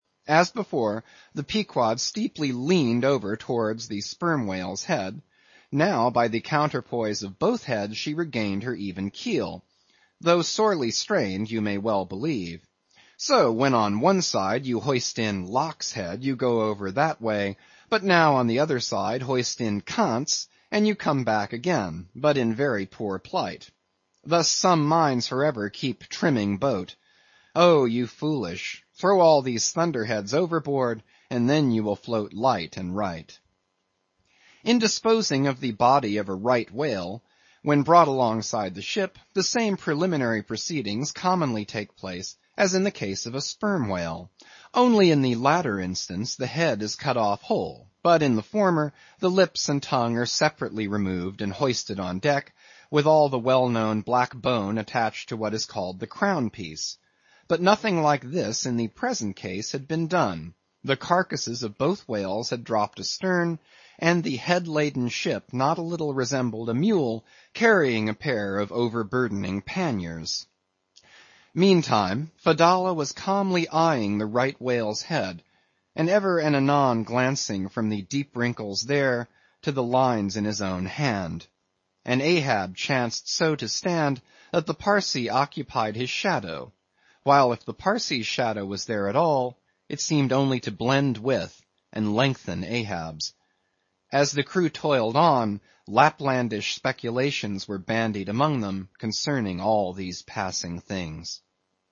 英语听书《白鲸记》第667期 听力文件下载—在线英语听力室